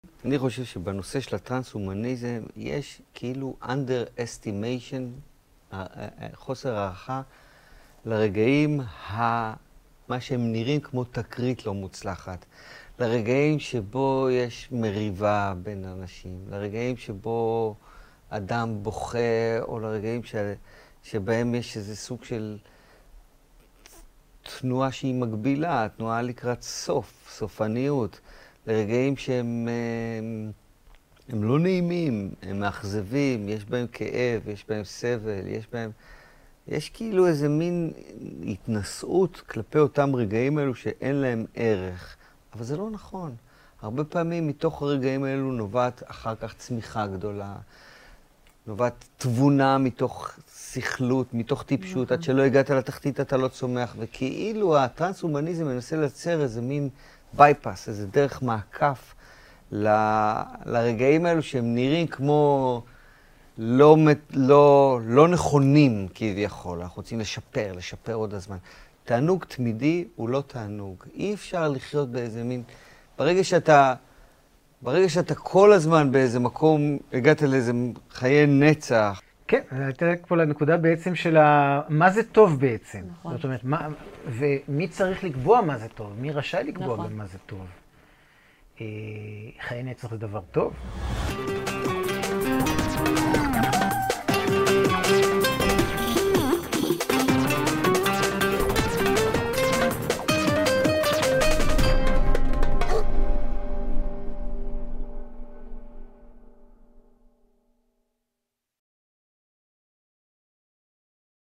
מתוך השיחה המלאה על טראנסהומניזם. מי רשאי לקבוע מה טוב?